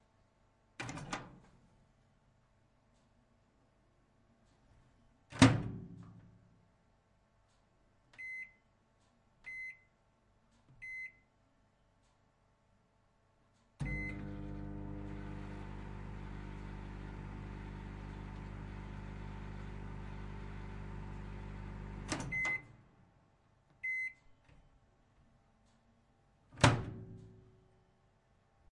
微波炉打开关闭按钮运行
标签： 打开 关闭 微波炉 OWI
声道立体声